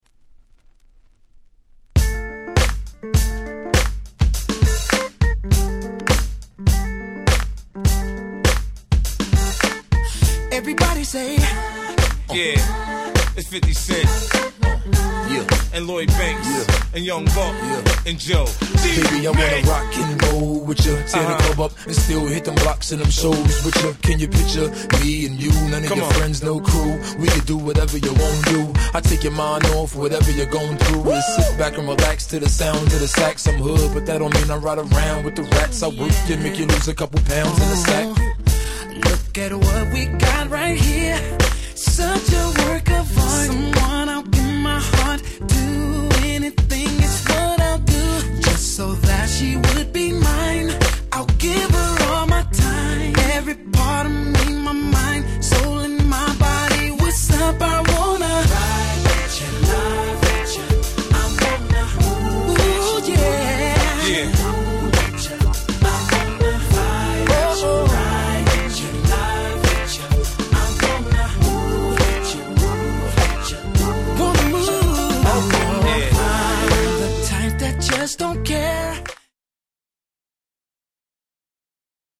04' Big Hit R&B !!